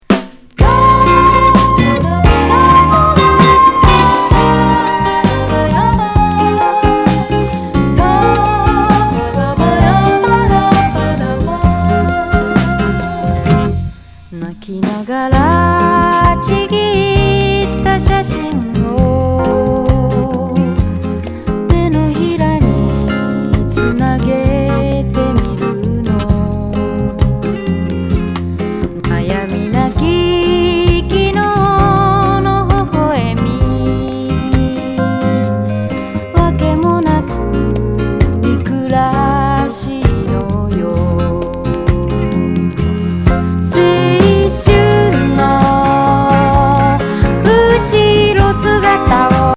スムース・シャバダバ・イントロから極上メロウ・アンニュイSSW!